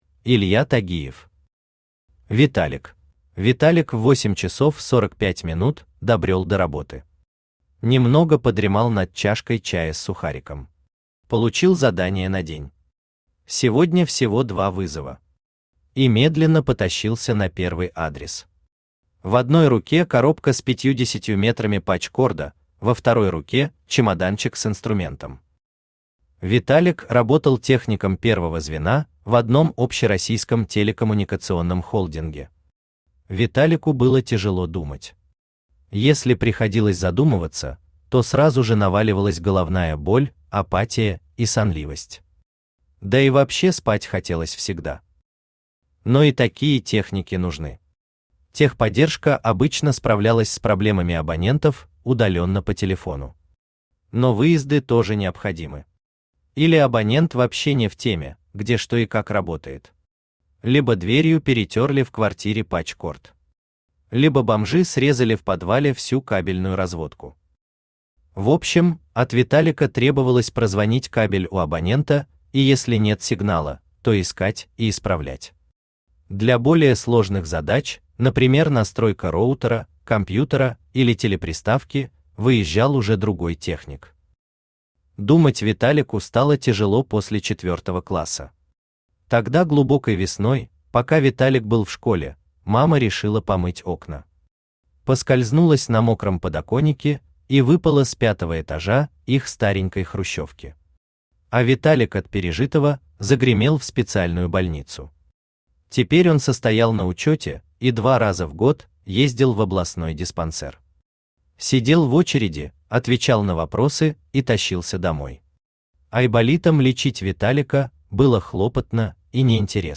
Aудиокнига Виталик Автор Илья Михайлович Тагиев Читает аудиокнигу Авточтец ЛитРес.